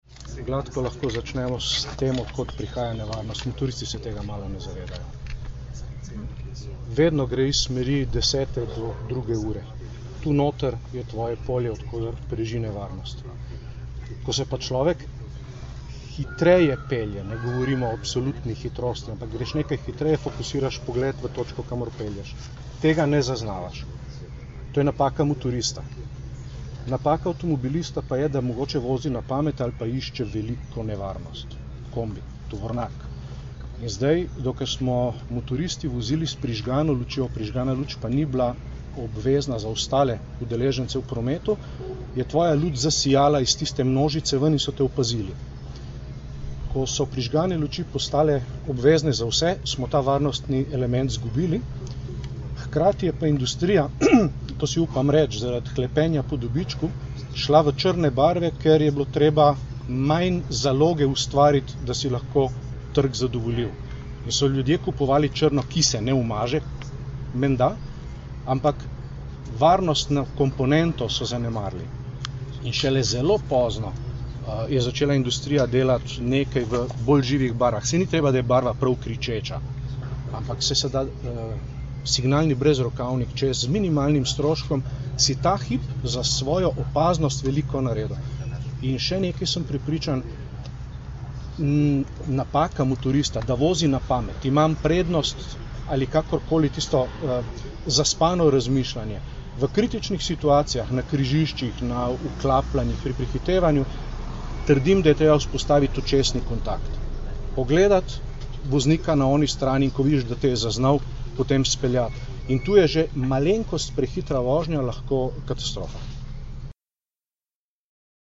Skupaj smo jih zbrali danes, 13. junija 2011, v Policijski akademiji v Tacnu, kjer smo organizirali novinarsko konferenco.